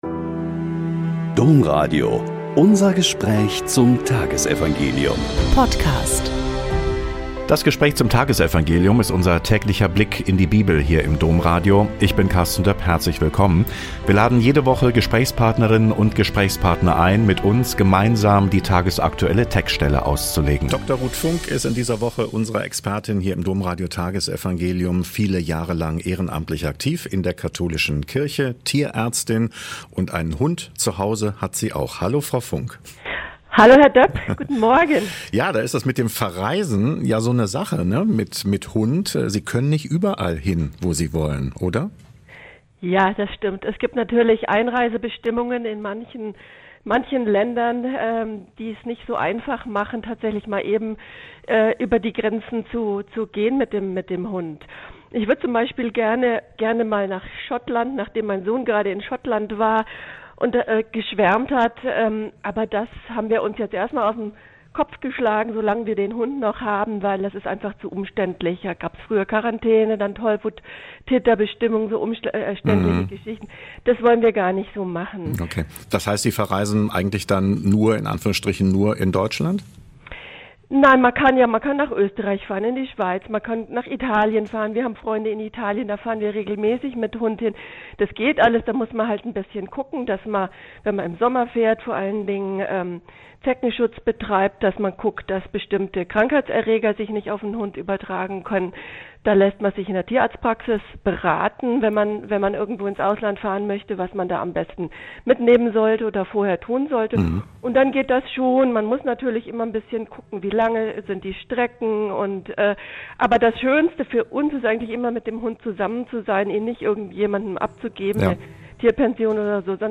Mk 6,45-52 - Gespräch